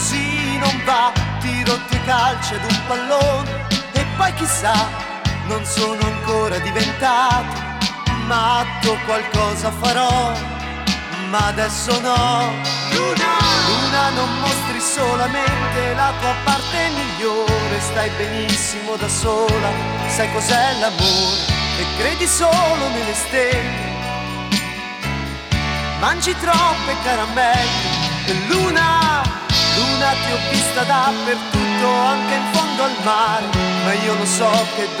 1980-01-01 Жанр: Поп музыка Длительность